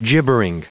Prononciation du mot gibbering en anglais (fichier audio)
Prononciation du mot : gibbering
gibbering.wav